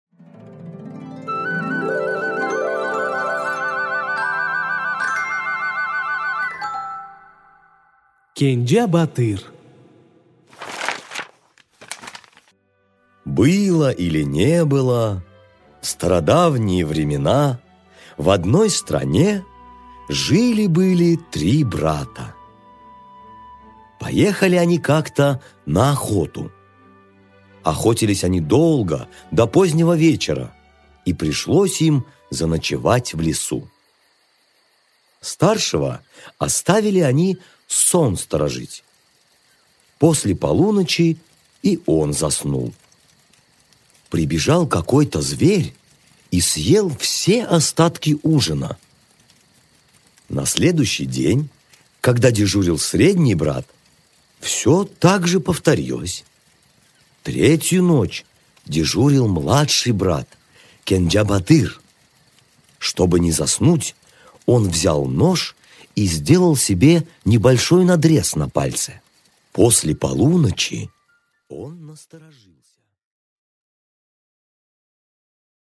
Аудиокнига Кенджа-батыр | Библиотека аудиокниг